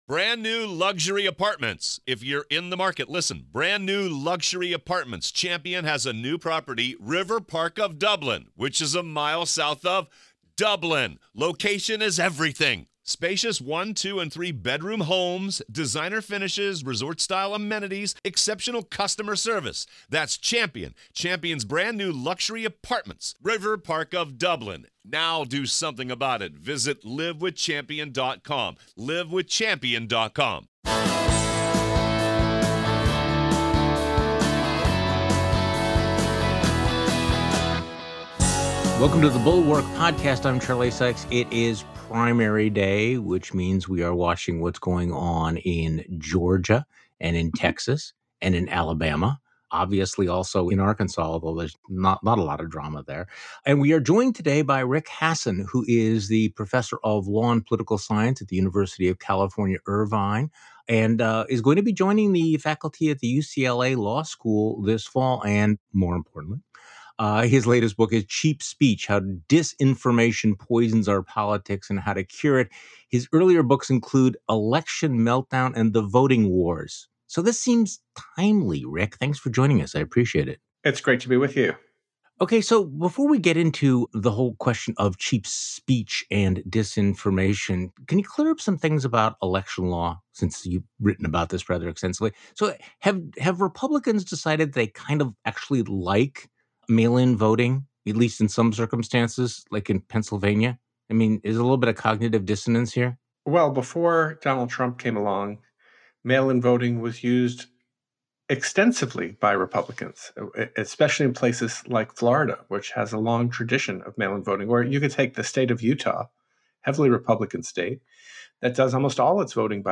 Misinformation, disinformation and other 'cheap speech' has voters confused about truth and lies, and is threatening our democracy. Law professor and author Rick Hasen joins Charlie Sykes on today's podcast.